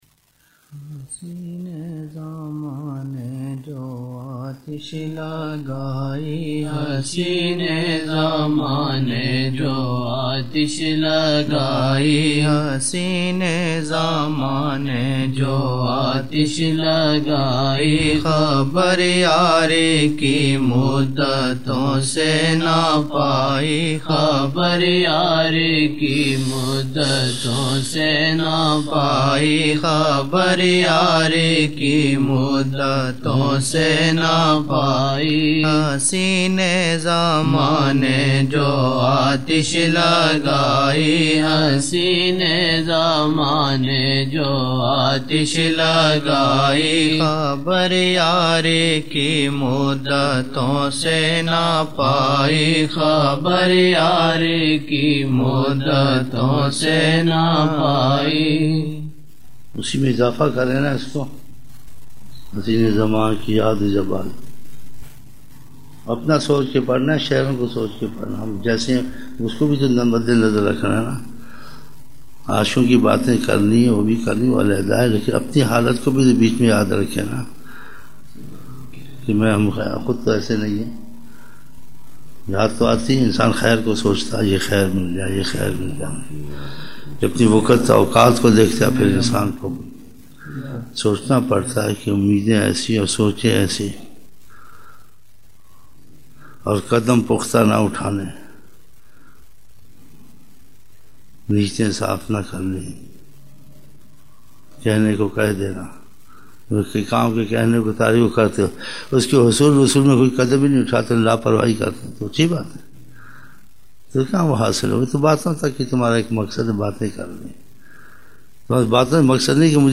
25 November 1999 - Zohar mehfil (17 Shaban 1420)